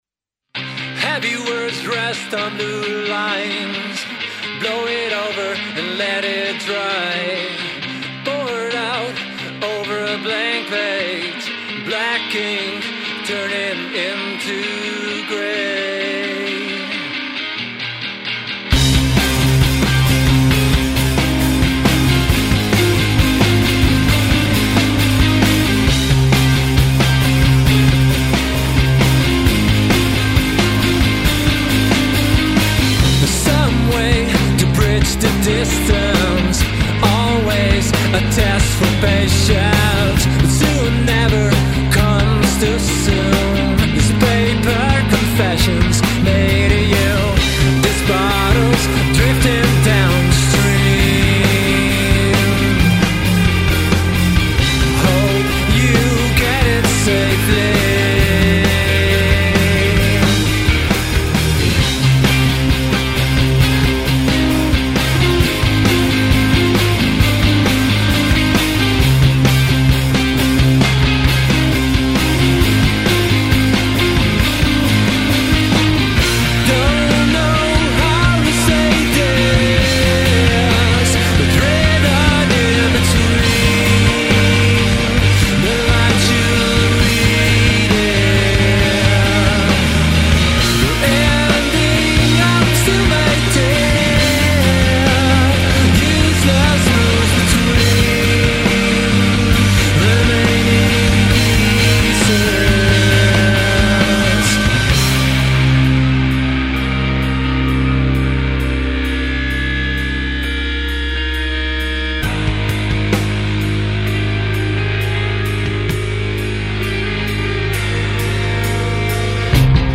Fijne opzwepende gitaren